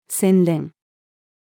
洗練-refining-female.mp3